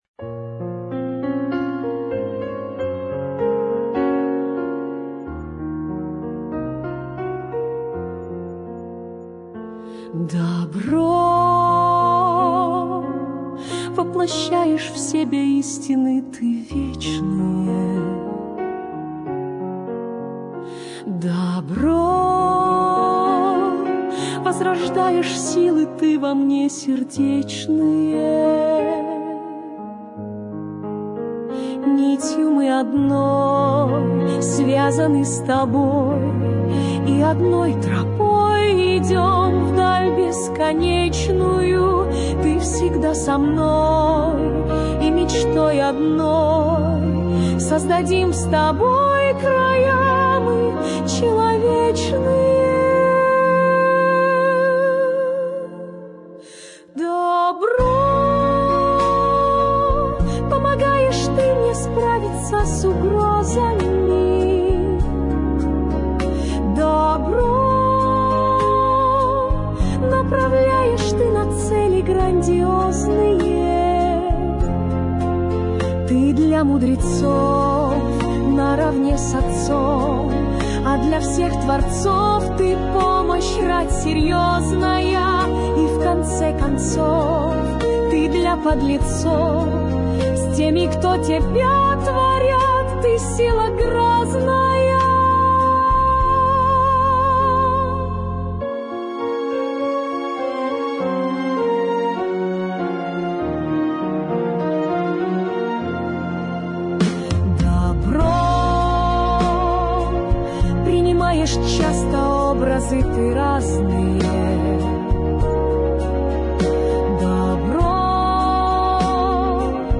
кавер-версия на мотив песни